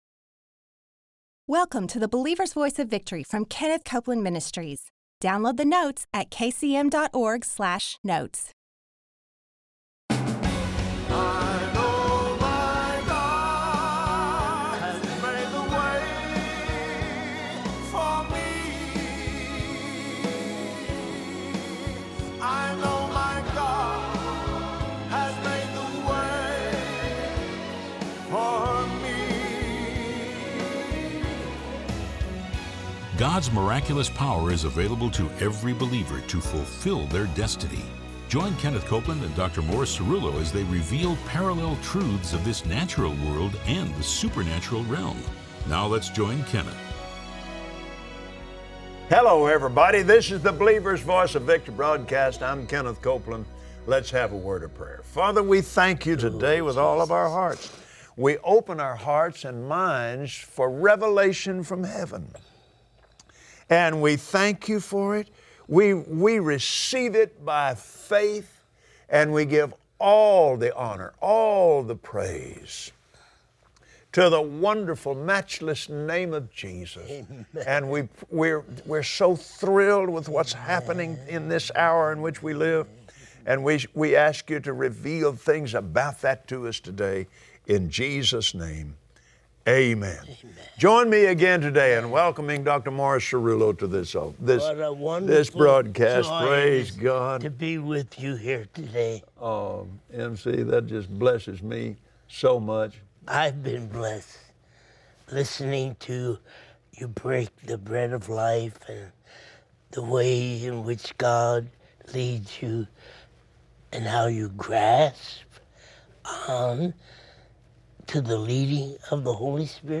Believers Voice of Victory Audio Broadcast for Friday 03/10/2017 Watch Kenneth Copeland and Dr. Morris Cerullo on the BVOV broadcast share the key to walking out God’s destiny. Humble yourself before God, and He will use you in great ways.